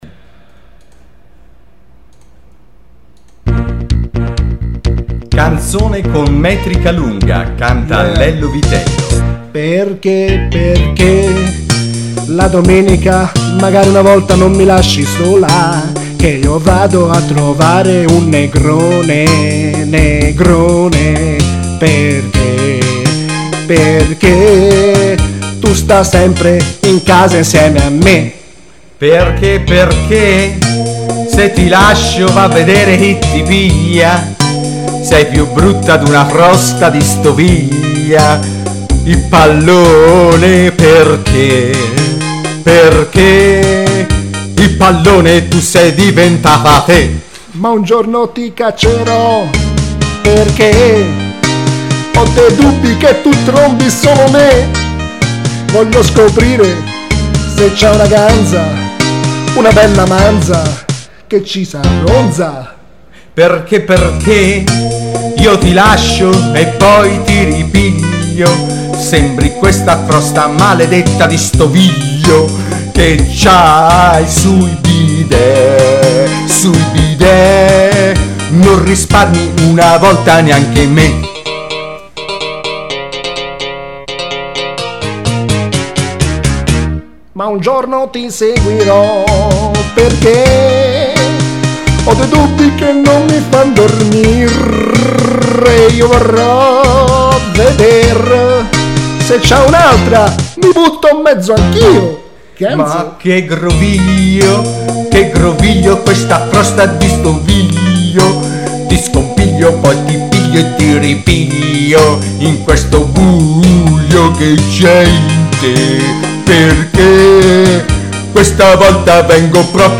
Una cover istantanea